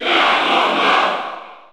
Crowd cheers (SSBU) You cannot overwrite this file.
Ganondorf_Cheer_French_NTSC_SSBU.ogg